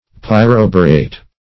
pyroborate - definition of pyroborate - synonyms, pronunciation, spelling from Free Dictionary Search Result for " pyroborate" : The Collaborative International Dictionary of English v.0.48: Pyroborate \Pyr`o*bo"rate\, n. (Chem.) A salt of pyroboric acid.